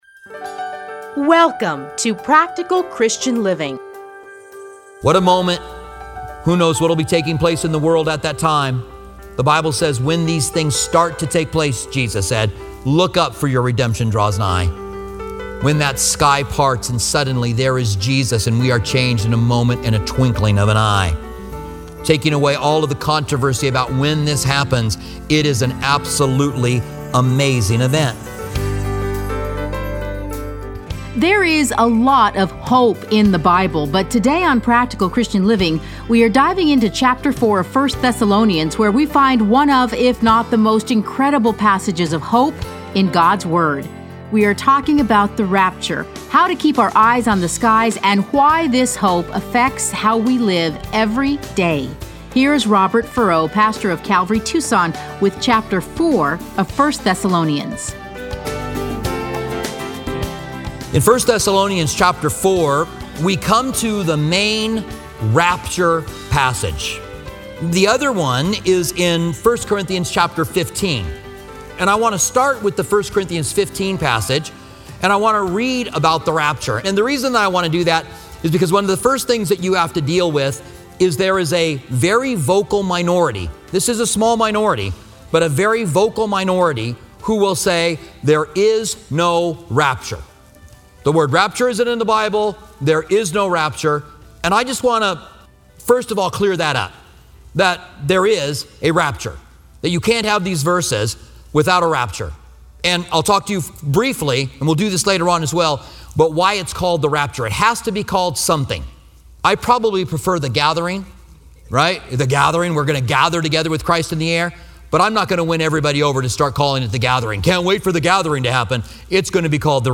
Listen to a teaching from 1 Thessalonians 4:1-12.